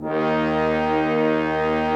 BRASS 3D#3.wav